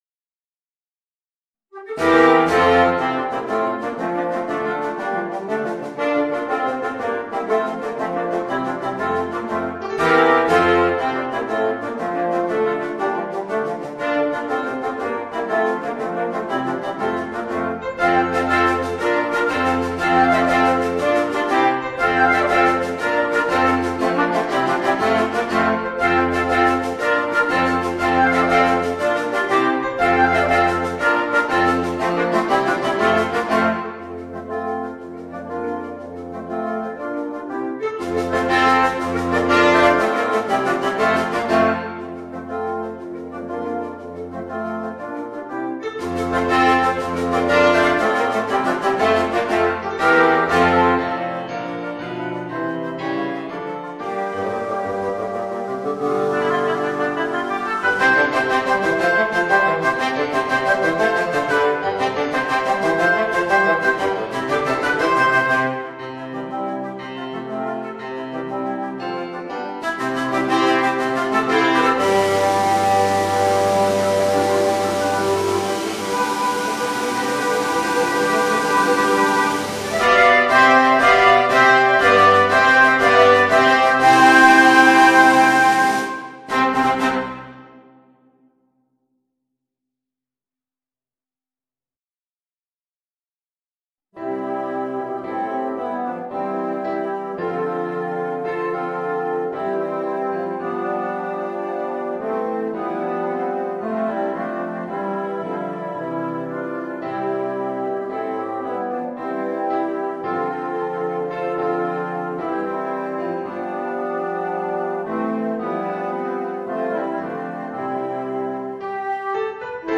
Gattung: Woodwind Quintet
A4 Besetzung: Ensemblemusik für 5 Holzbläser PDF